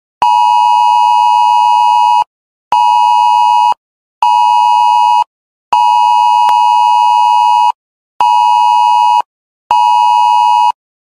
להורדה | התראות עם צילצול אחר
סאונד רשמי פיקוד העורף.mp3